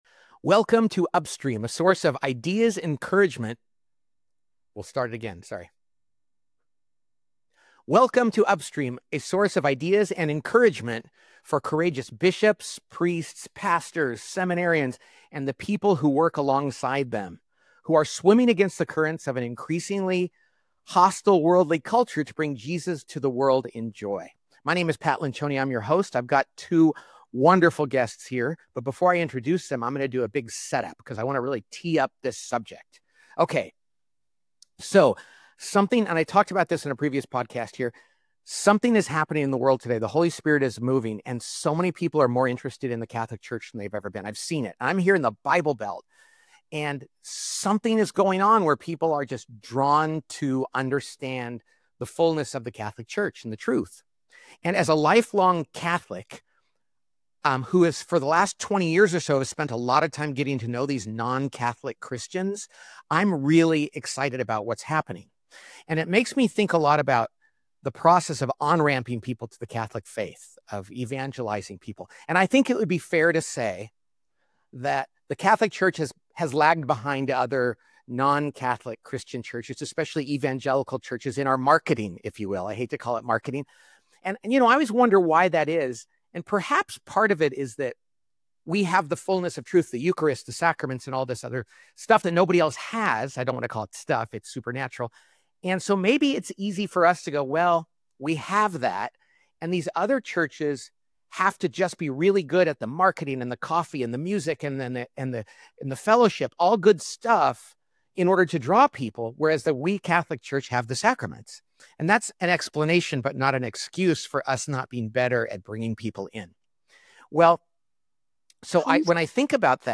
The three of them have a spirited conversation about OCIA and what it really means to initiate people into the Catholic church.